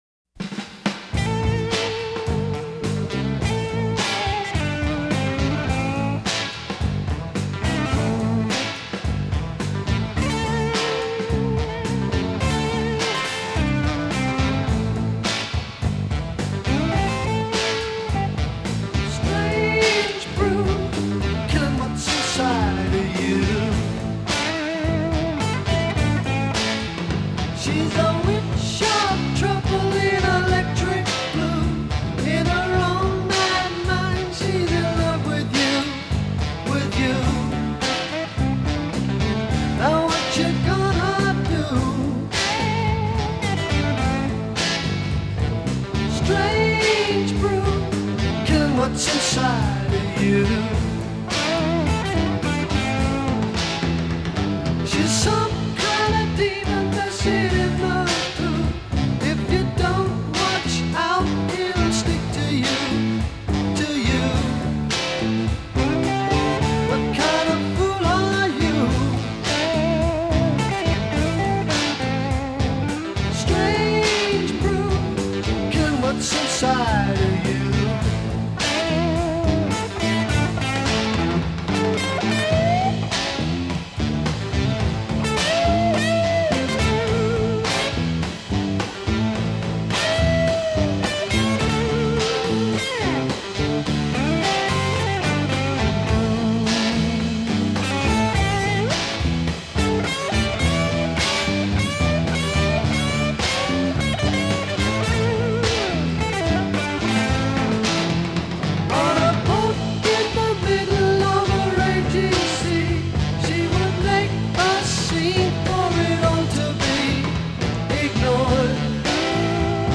A Introduction 8 Guitar solo over guitar, bass, and drums.
Refrain 4 Regular refrain plus guitar cadence. a